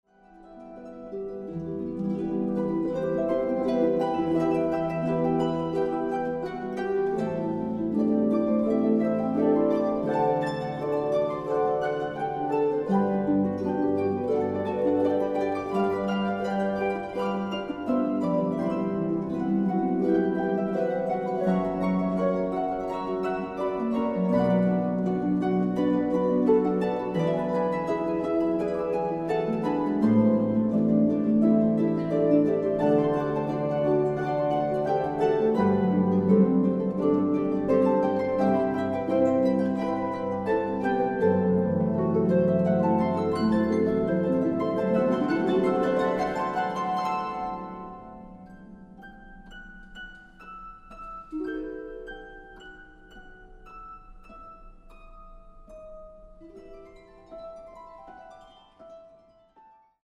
Arrangement for 8 or 16 Harps